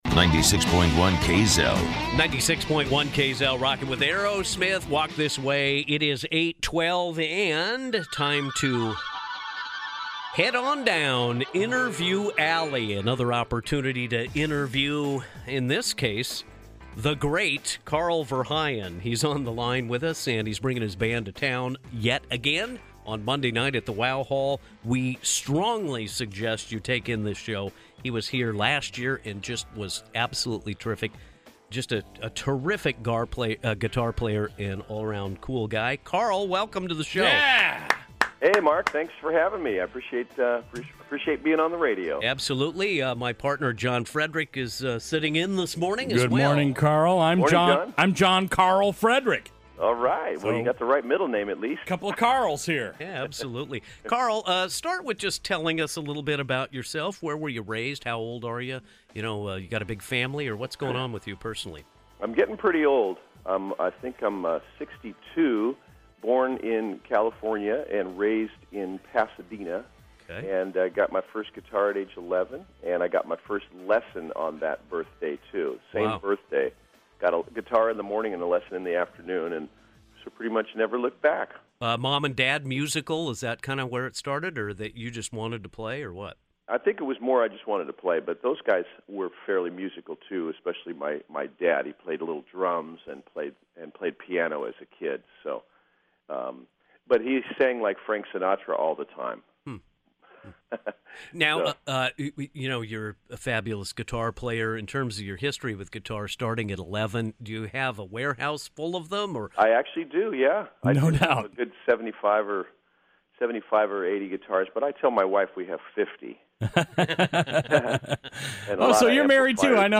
Carl Verheyen Interview 9/7/16